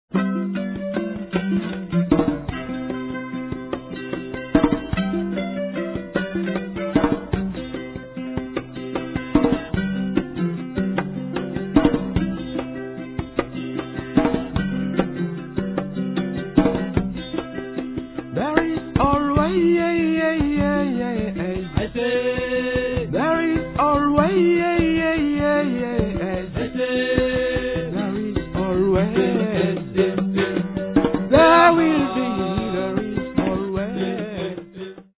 traditional Malagasy songs